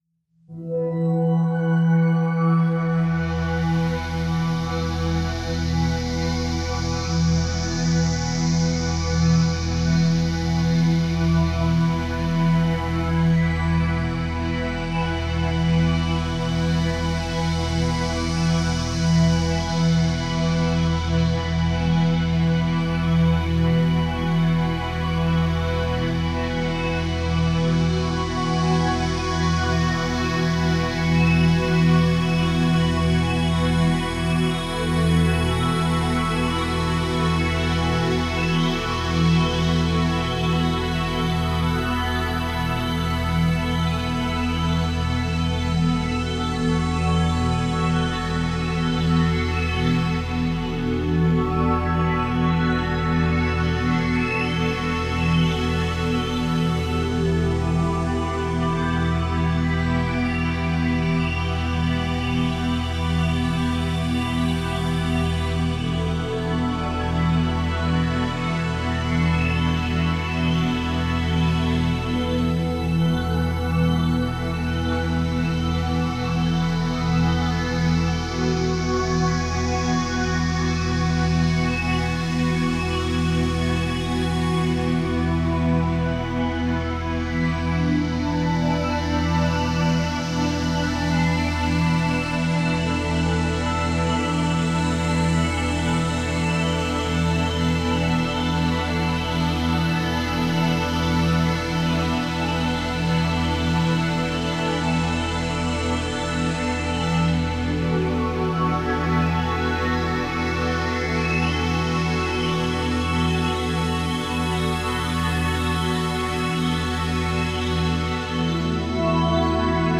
hypnotic musical tracks